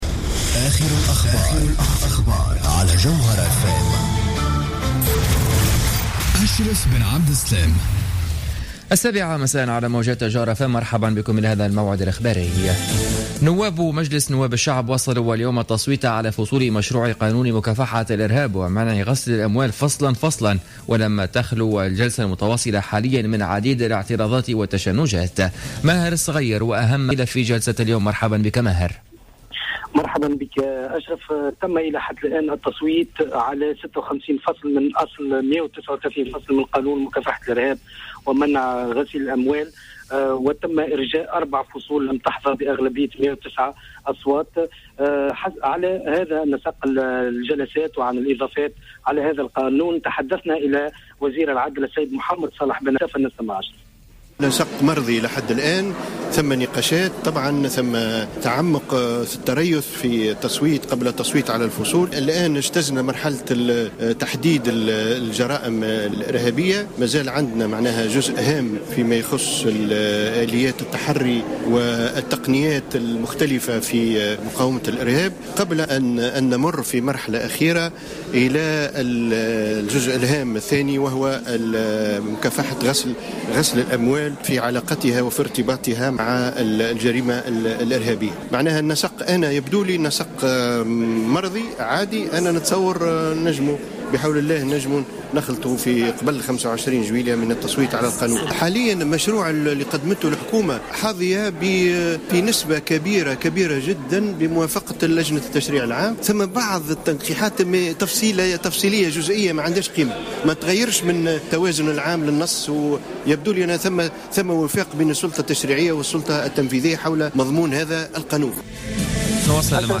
نشرة أخبار السابعة مساء ليوم الخميس 23 جويلية 2015